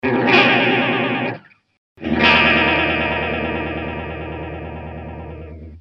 Аналогвый хорус,т.н. "мод Small Clone".
Звук чистый,тру байпасс. По сравнению с хорусом в G-Major, сабж звучит очень прозрачно.
Единственный,недостаток - моно выход.
Семплы: